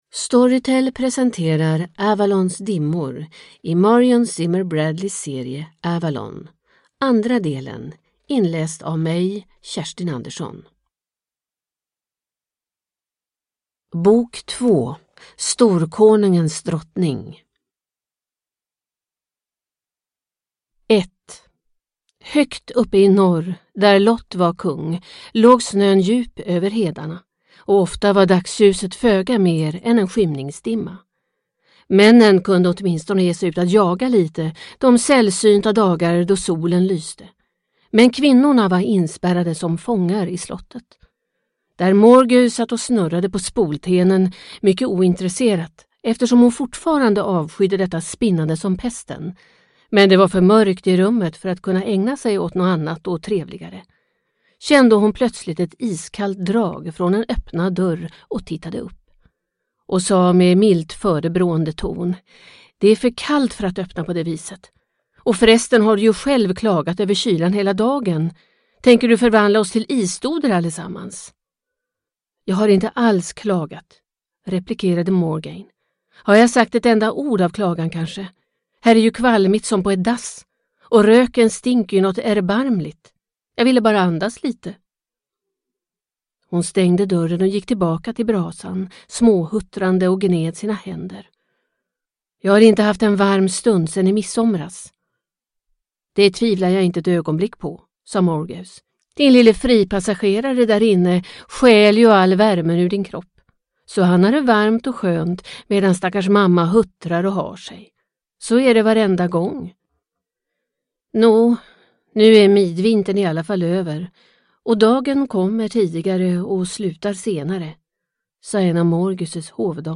Avalons dimmor – del 2 – Ljudbok – Laddas ner